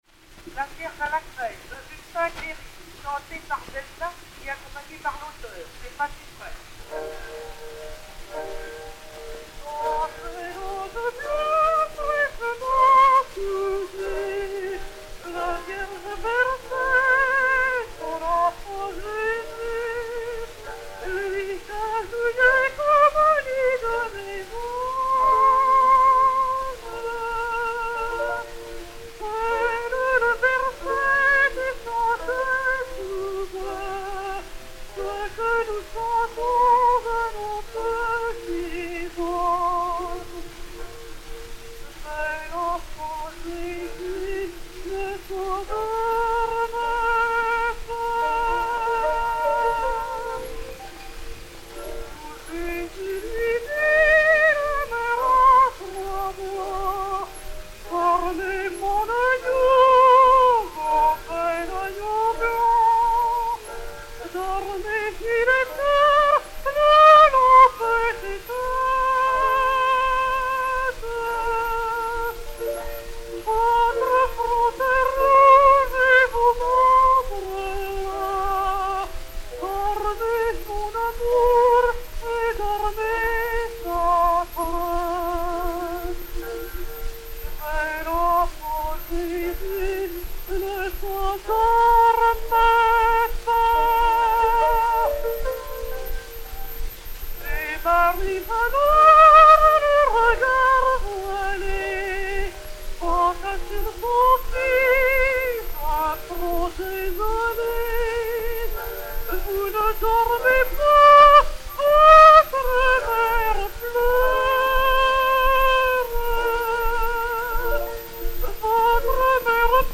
Marie Delna et Justin Clérice (piano)
Pathé saphir 90 tours n° 3516, enr. en 1903/1904